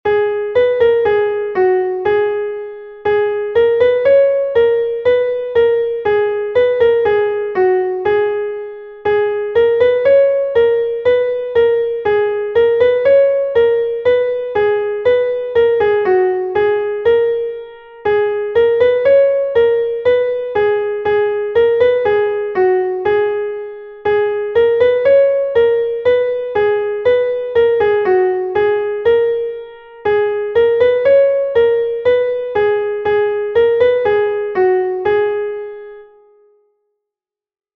Hanter dro